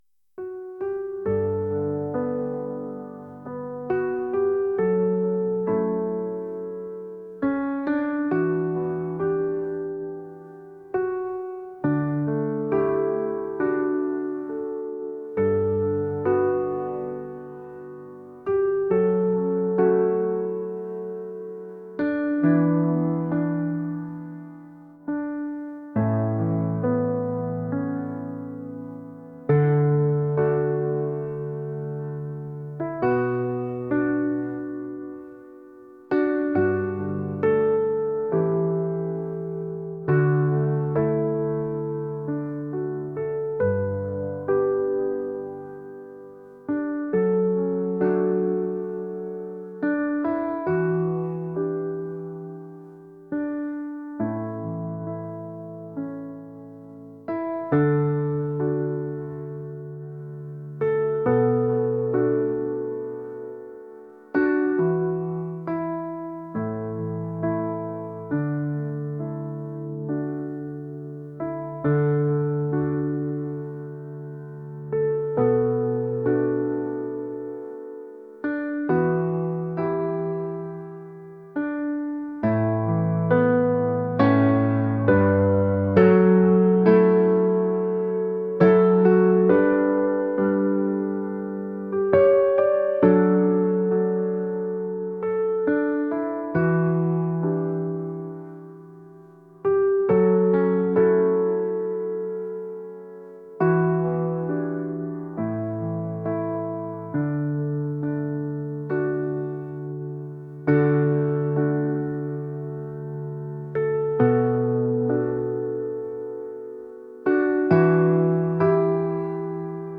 dreamy | pop